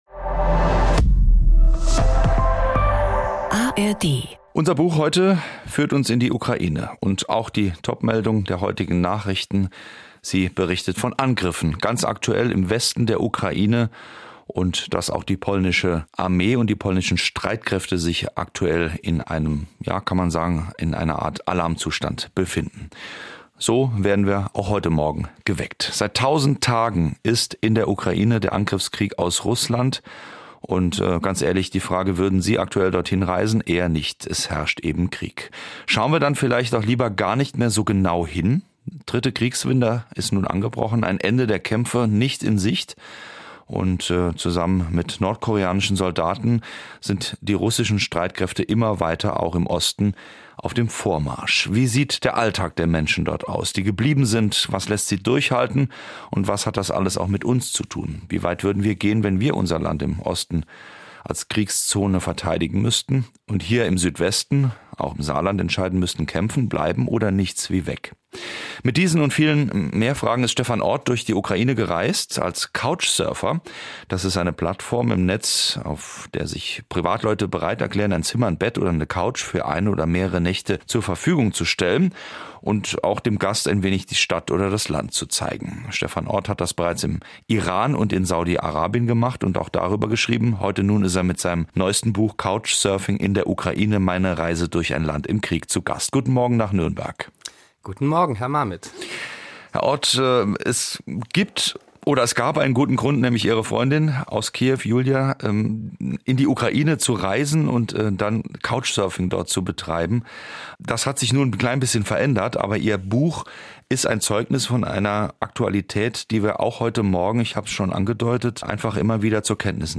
Die traditionsreichste Sachbuchsendung im deutschen Sprachraum stellt seit über 50 Jahren jeweils ein Buch eines Autors eine Stunde lang im Gespräch vor. Die Themen reichen von Politik und Wirtschaft bis zu Gesundheit, Erziehung oder Psychologie.